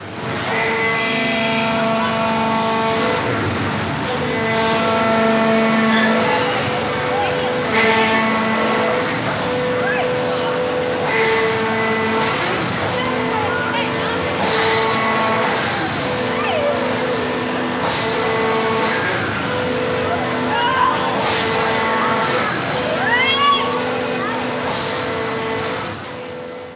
Parque de atracciones: el barco